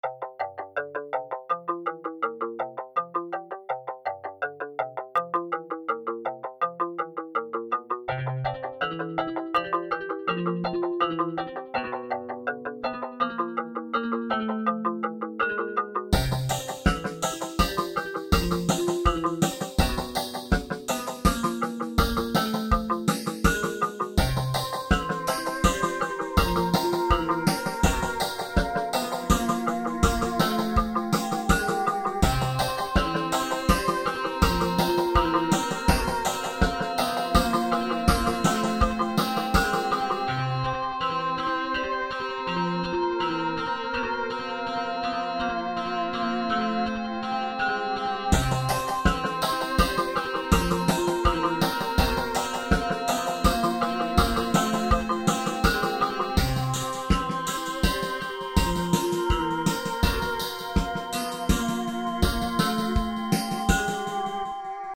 I bought a guitar simulator for FL Studio, and did roughly what you’d expect from that combination.
Same tools, different atmosphere:
Feb21-softer.mp3